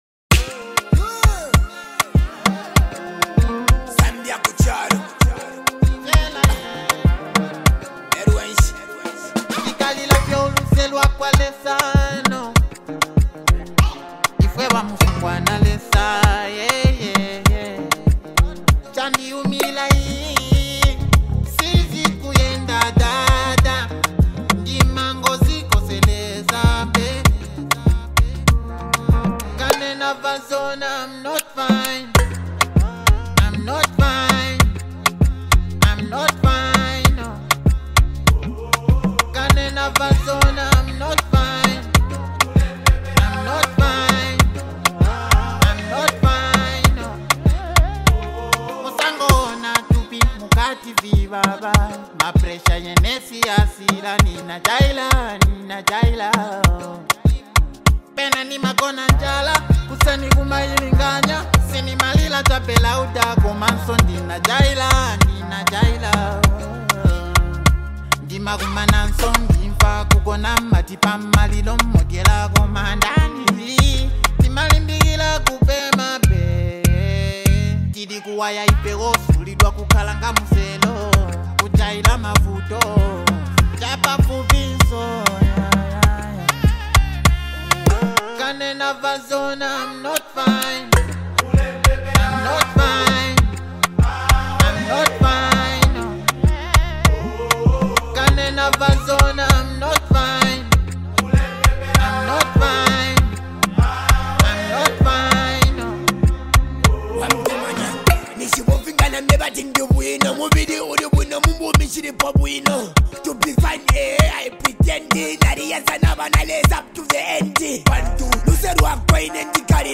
emotional track